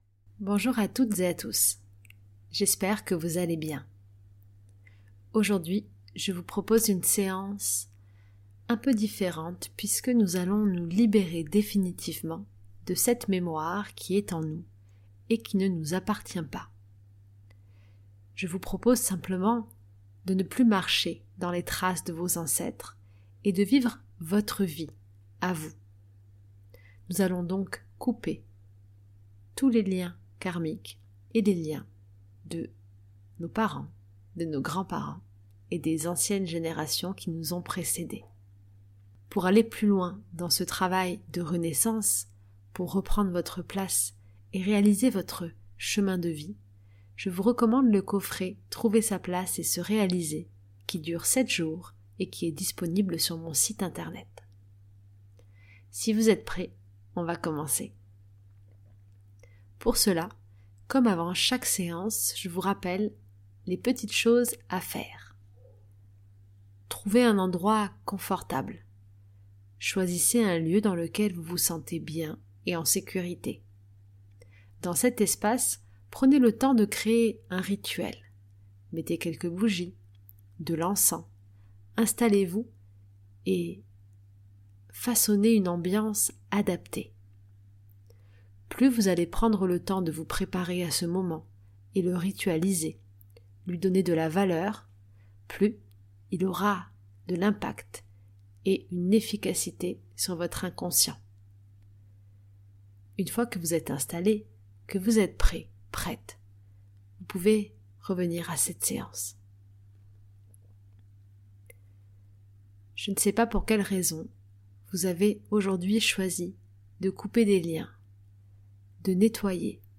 MÉDITATIONS KARMIQUES